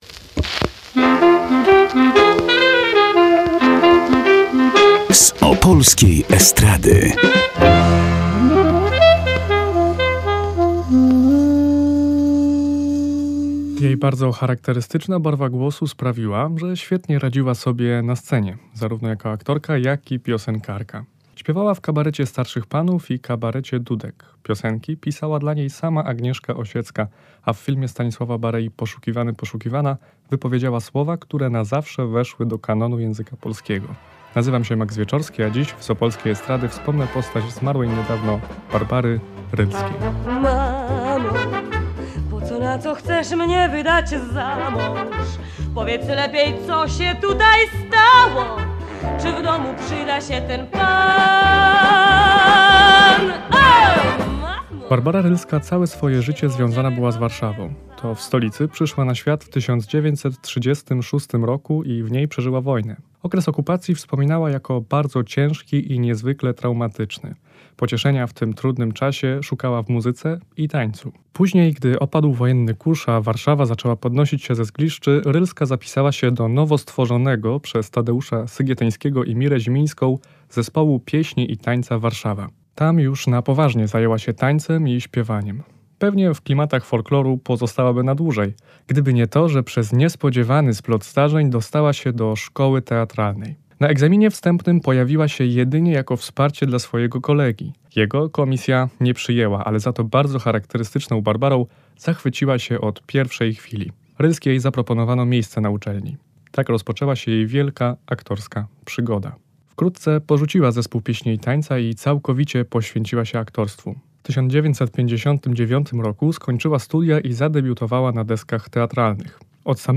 Jej bardzo charakterystyczna barwa głosu sprawiła, że świetnie radziła sobie na scenie – zarówno jako aktorka, jak i piosenkarka.